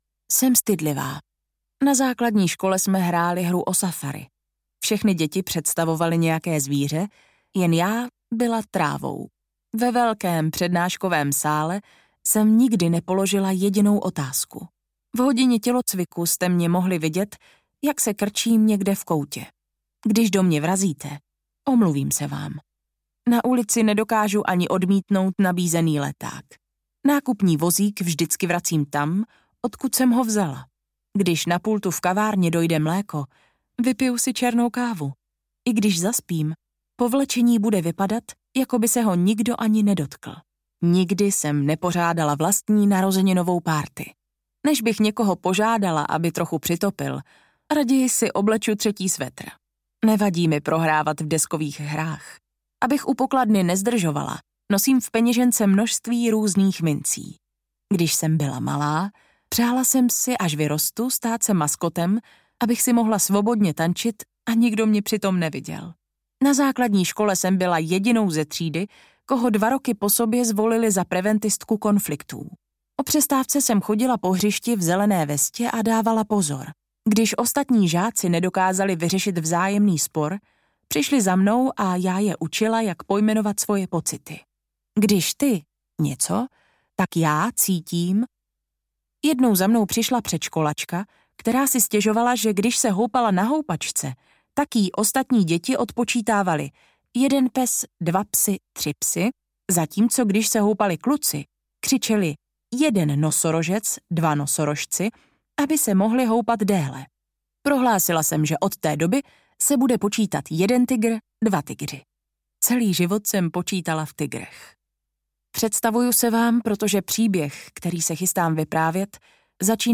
Audiokniha Mám jméno | ProgresGuru
audiokniha